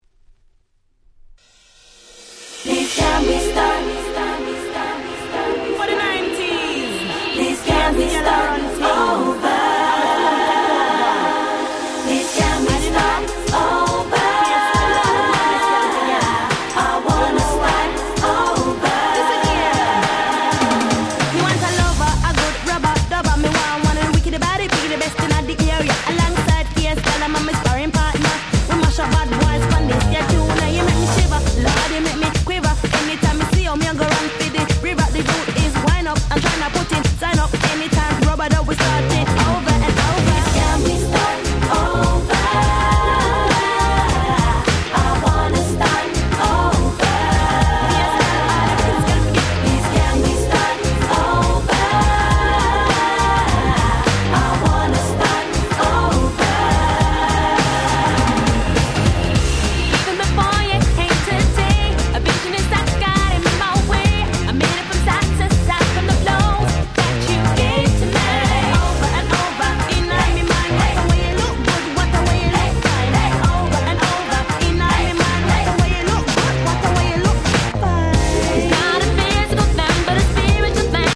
99' Nice EU R&B !!